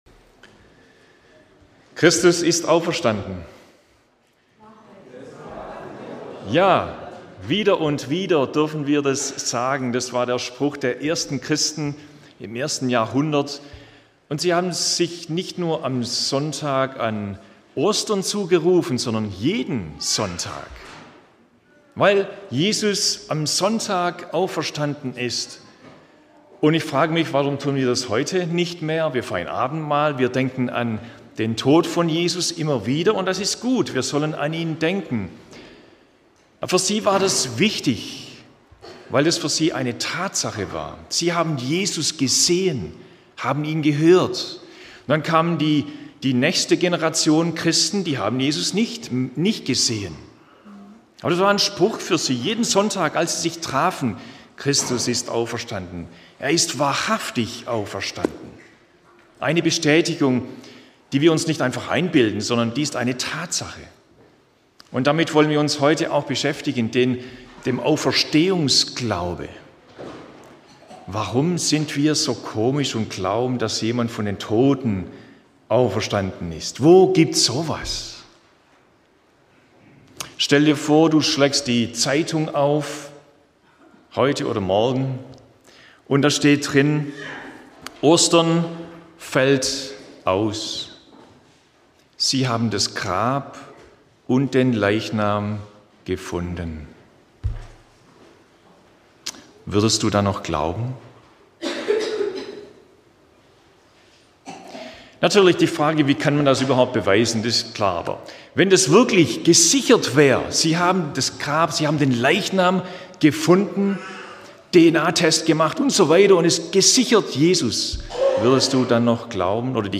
Deshalb wünschen wir dir, dass du mit diesen Predigten Gott erlebst.